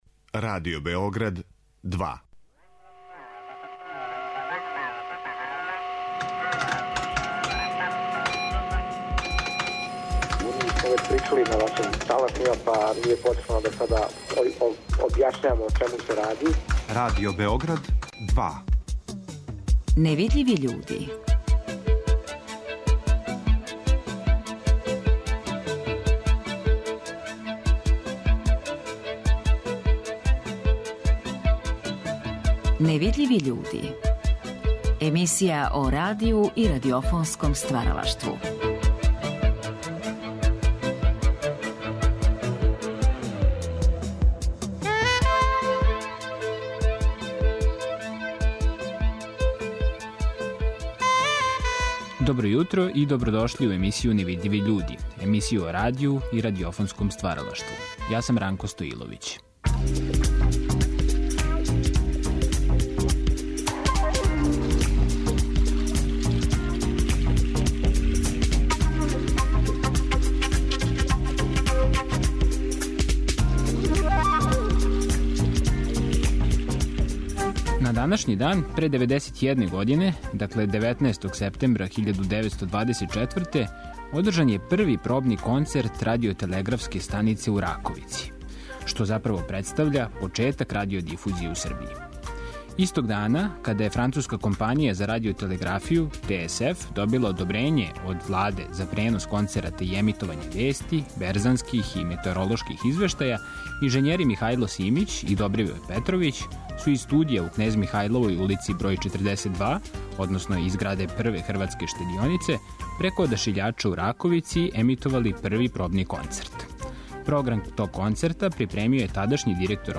Говорићемо о установљењу серије, о њеној предисторији, најзначајнијим ауторима, наградама, уредничким печатима, као и о манифестацији "Дани Радионице звука". Имаћете прилику да чујете и фрагменте неких од награђених дела на најзначајнијим светским фестивалима радиофоније.